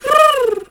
pigeon_2_emote_02.wav